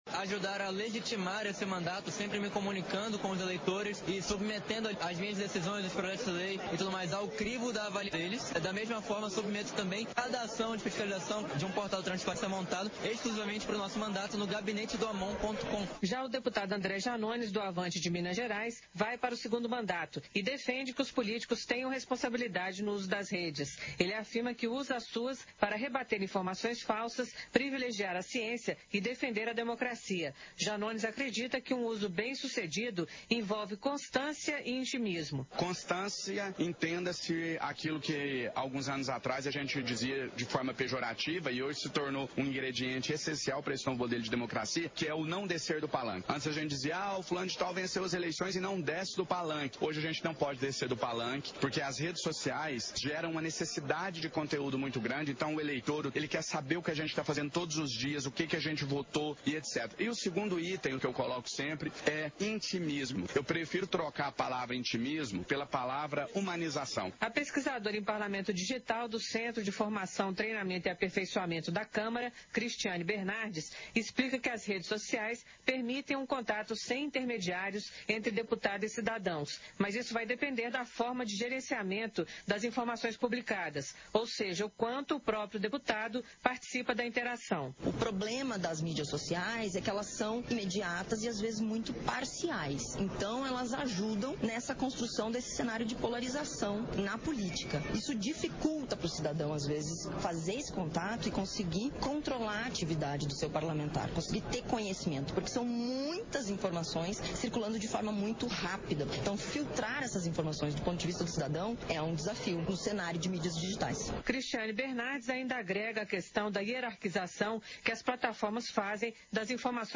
Sessão Ordinária 02/2023